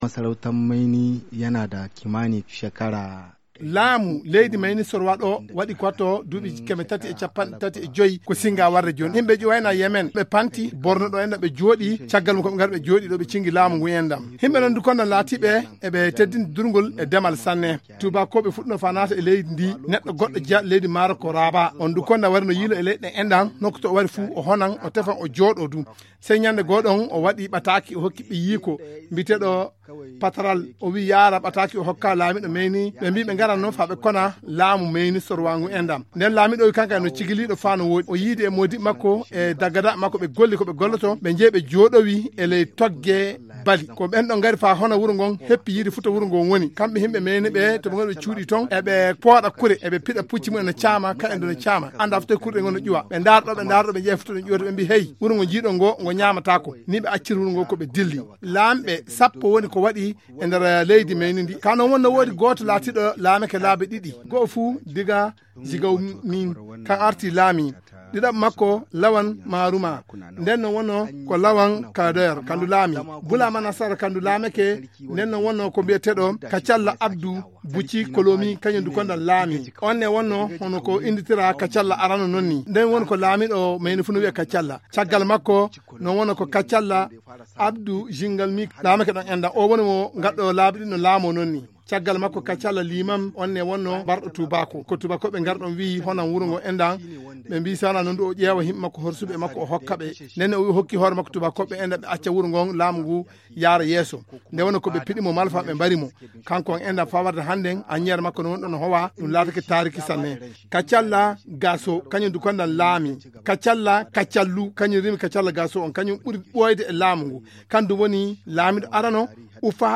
Propos recueillis à Mainé Soroa